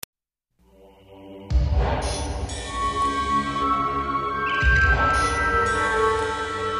CLIMA DE SUSPENSOAMBIENTE, CLIMA DE SUSPENSO
EFECTO DE SONIDO DE AMBIENTE de CLIMA DE SUSPENSOAMBIENTE, CLIMA DE SUSPENSO
Clima_de_suspensoAmbiente,_clima_de_suspenso.mp3